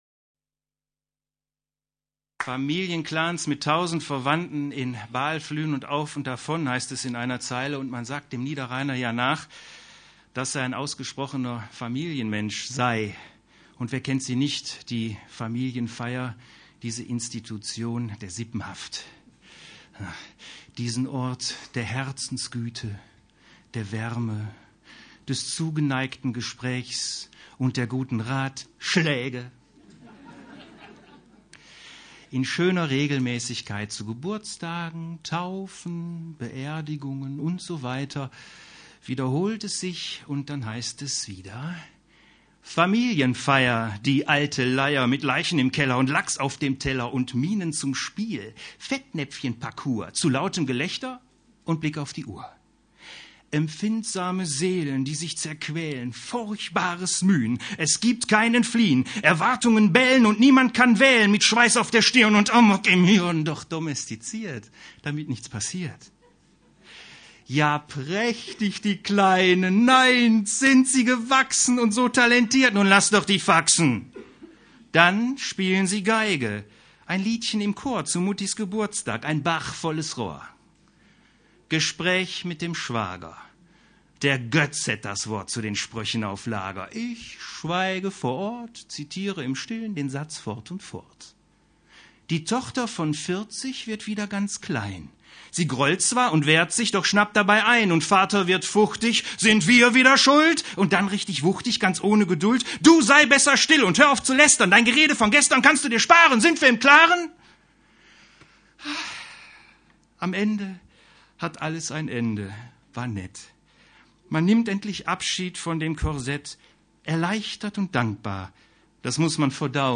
Liveaufnahme aus dem Hundert Meister, Duisburg